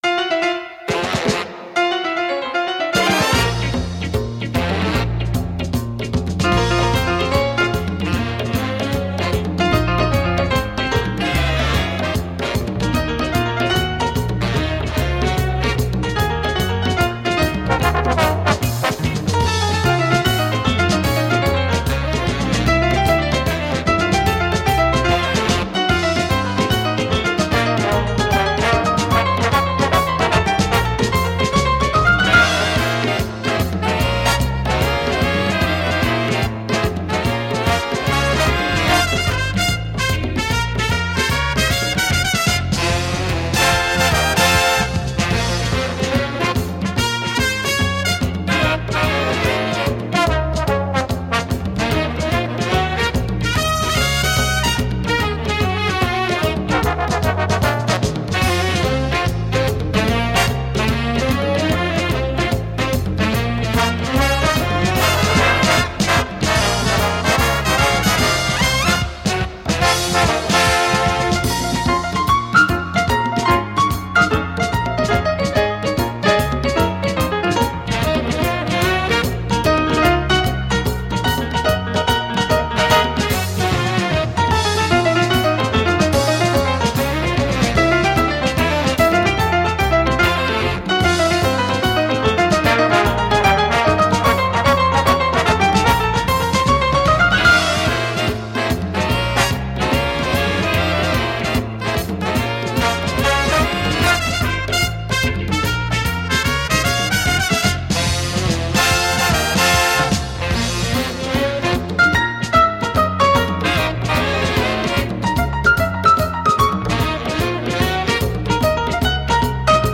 Жига